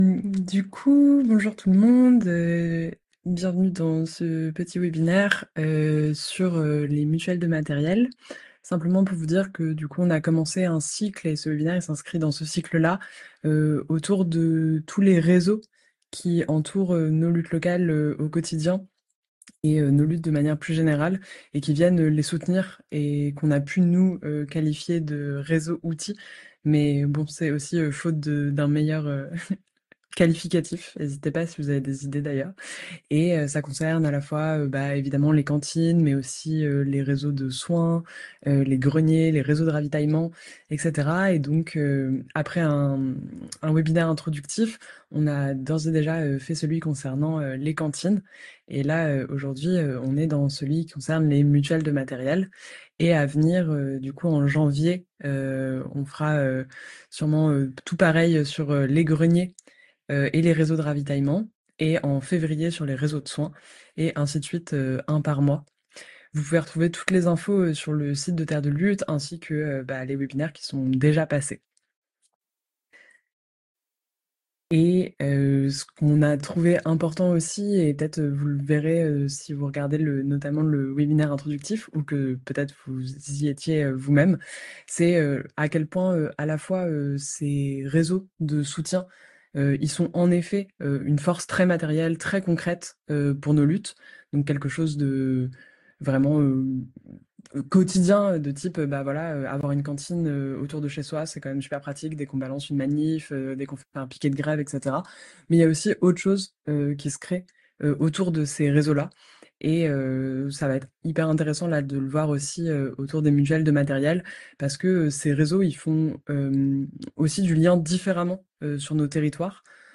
Webinaire - Les mutuelles de matériel
Intervenant·es : La Mutmat de Rennes La Matmouth de Bure La Mutante de l’Orne Les Soulèvements de la Terre